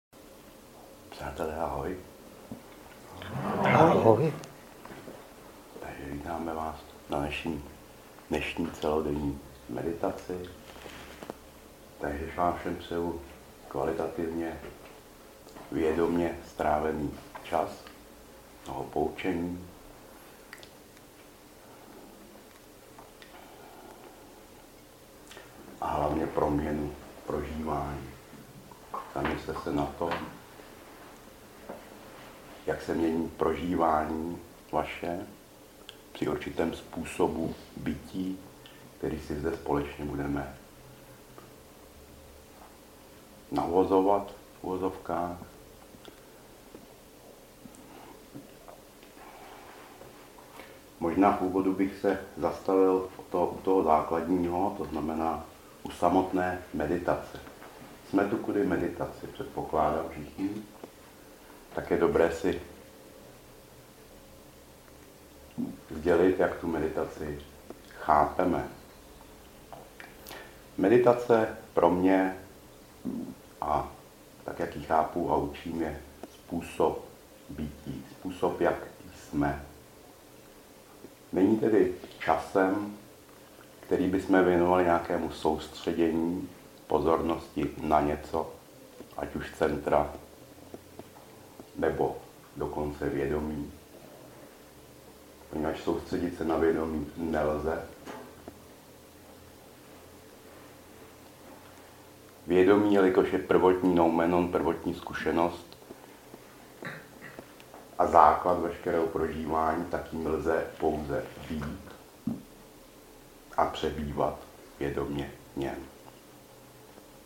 Obsah: Princip vědomí – kultivace vědomí celo-tělesného prožívání, 2. Kultivace prostorové subjektivity těla (jóga-um prostoru), 3. Kultivace vědomí dechu (jóga-um dechu), 4. Nétra-tantra. Nejedná se o studiovou nahrávku.